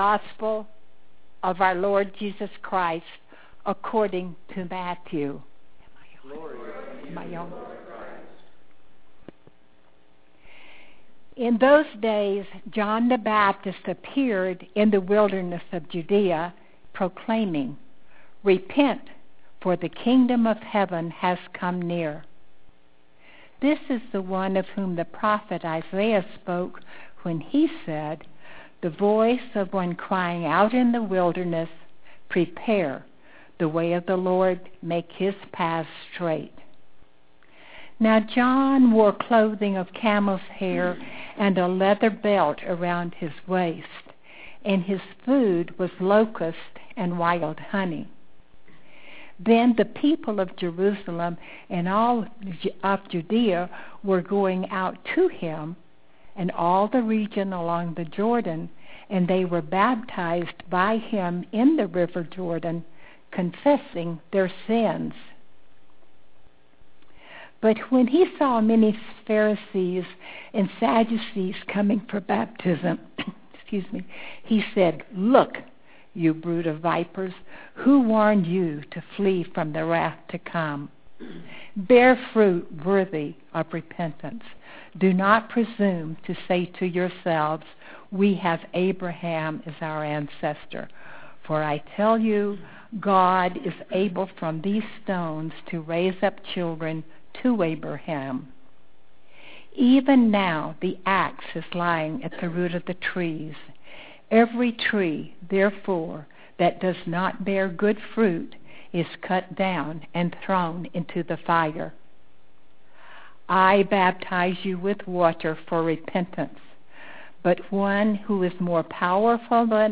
You can read the scripture by clicking on Luke 21:25-36 or listen to the gospel in our recording below.